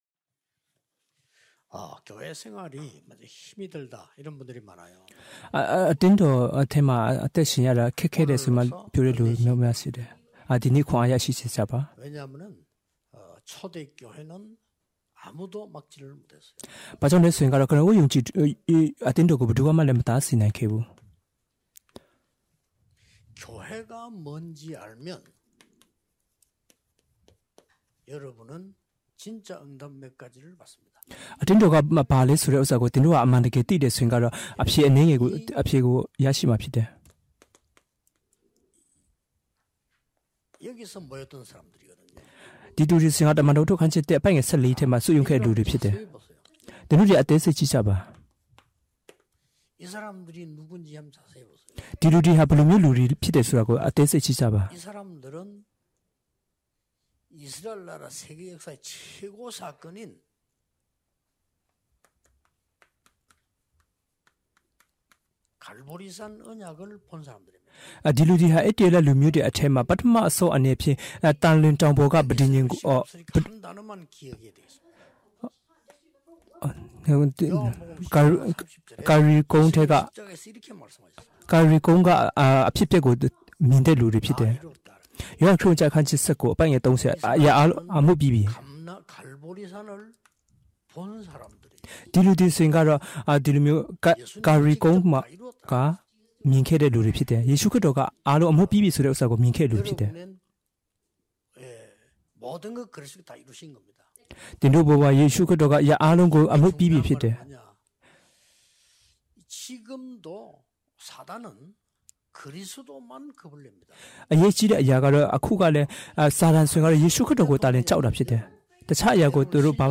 ၂၀၂၂နှစ် နှစ်သစ်ကူဆုတောင်းပွဲ။ 2022 သင်ခန်းစာ ၂ - အသင်းတော်၏ ၂၄ နာရီ (တ၂:၁-၄)